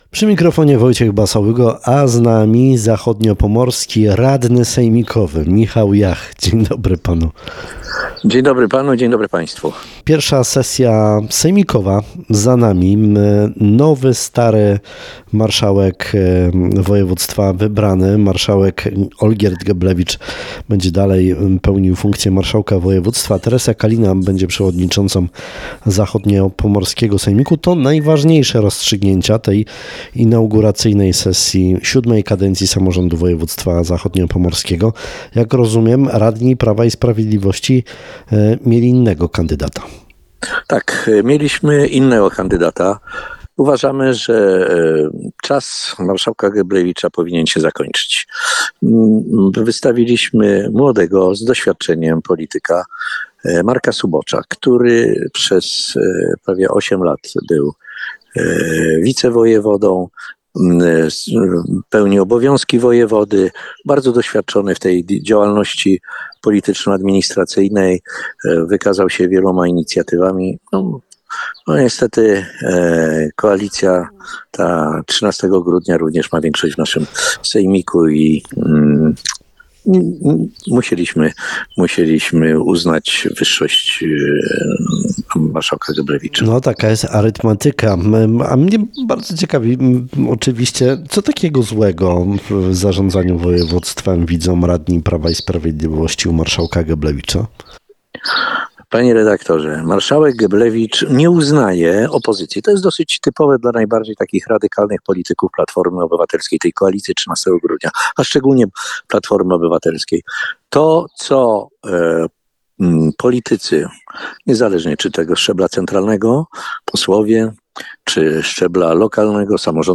Posłuchaj Rozmowy Dnia: